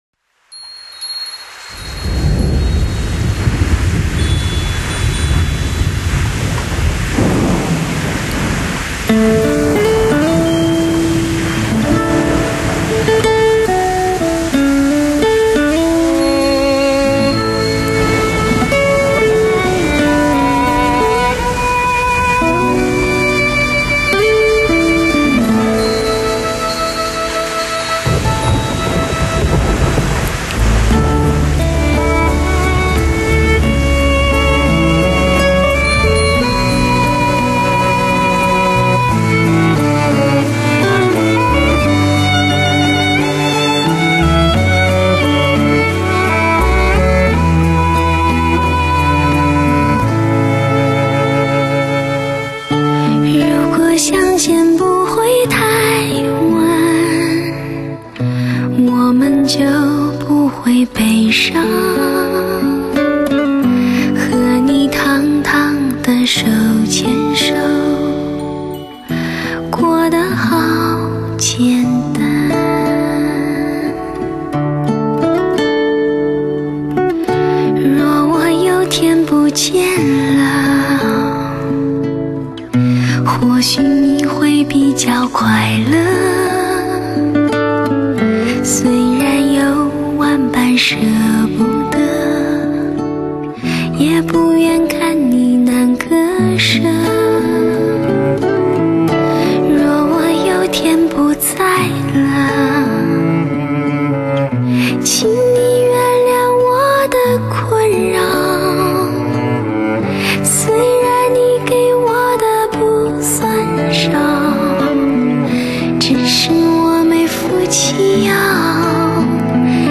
德国黑胶CD
收集绝版最经典 最发烧 最畅销 金牌情歌金曲 最悠扬的旋律......